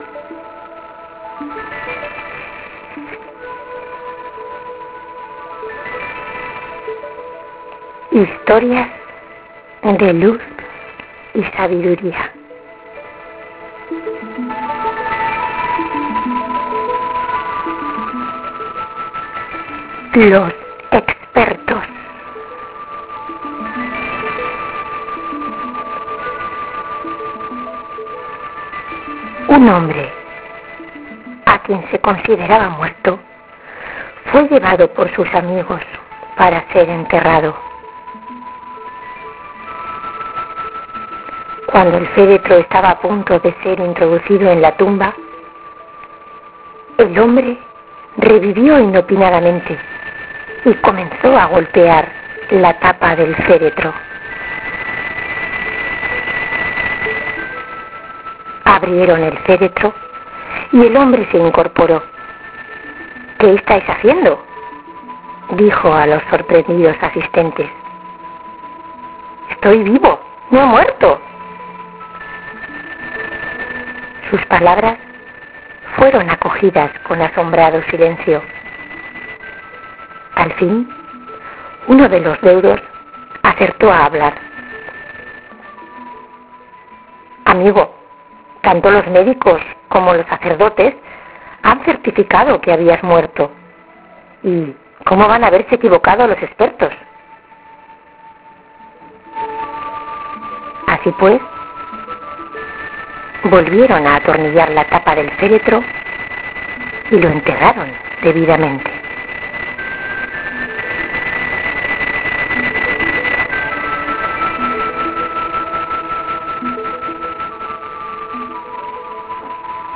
Ahora puedes bajarte esta historia narrada (491 kb)